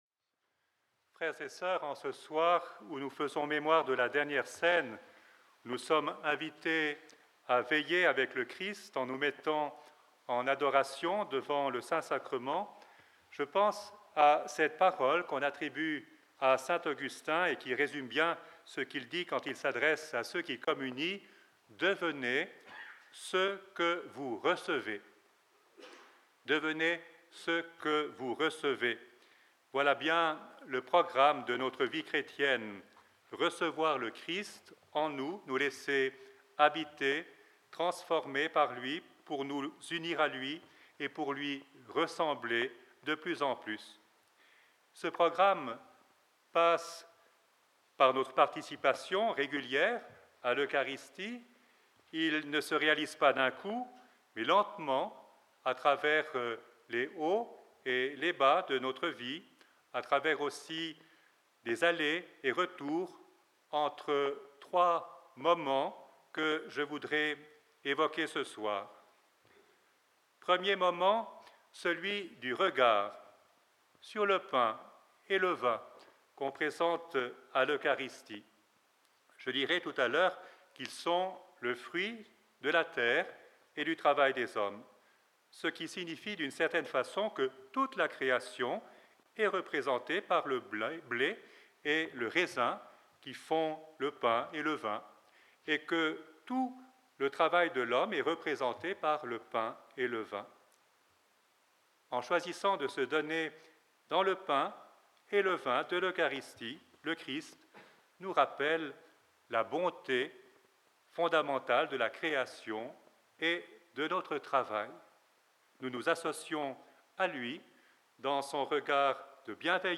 Ce soir dans la chapelle du couvent St-Hyacinthe les frères ont entendu l'homélie